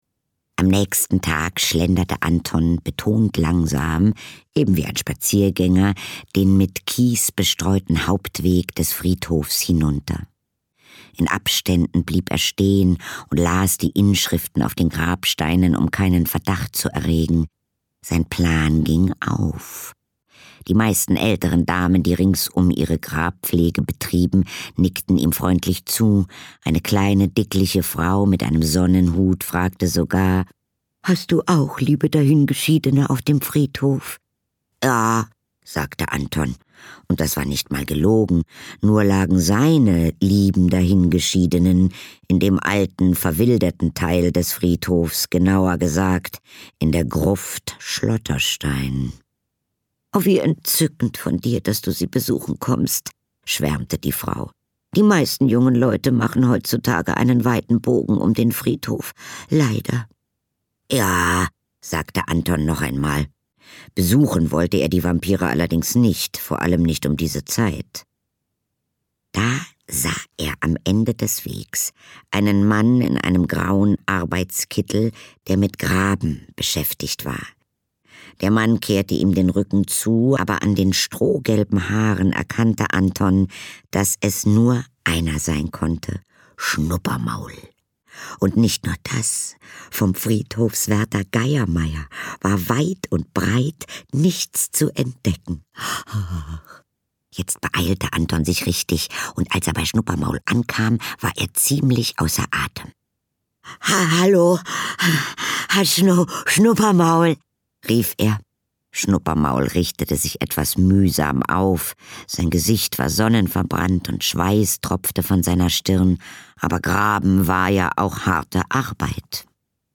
Der kleine Vampir und die Letzte Verwandlung Angela Sommer-Bodenburg (Autor) Katharina Thalbach (Sprecher) Audio Disc 2024 | 2.